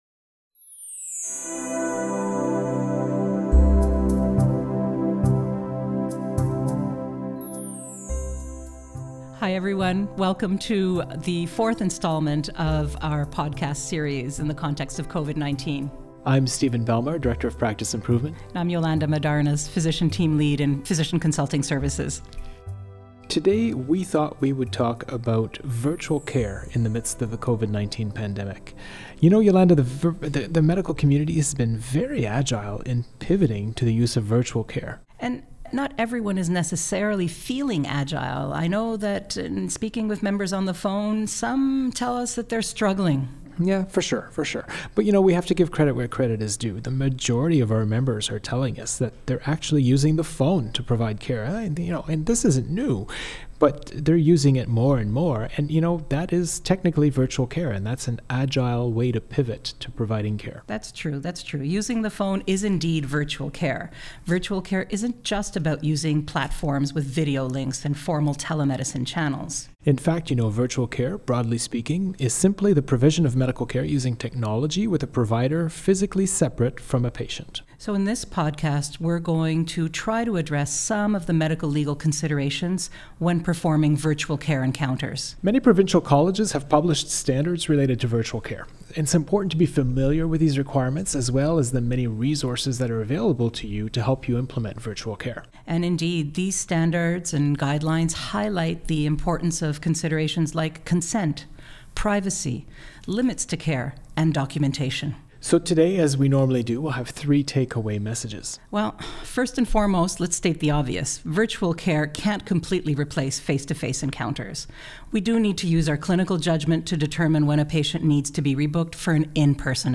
Listen to them discuss how to include considerations around clinical judgment, standard of care, informed consent and privacy to provide the best virtual care you can.